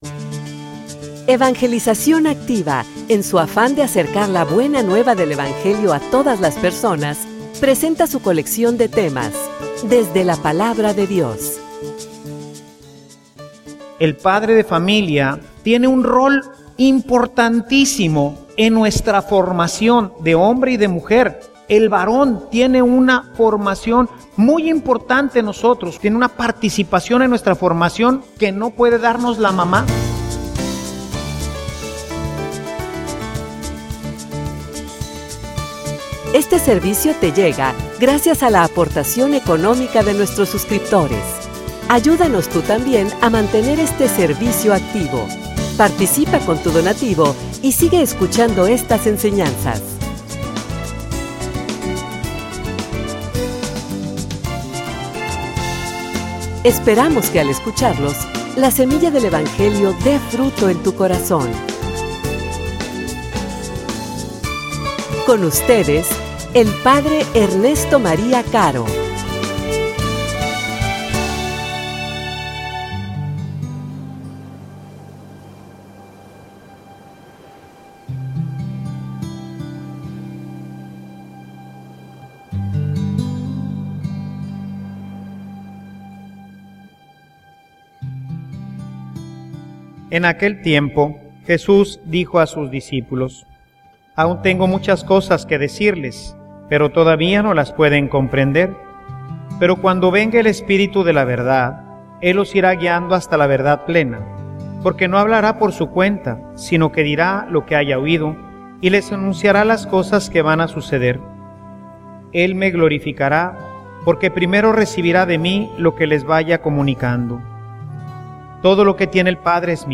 homilia_Relaciones_que_transforman.mp3